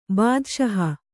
♪ bādaṣaha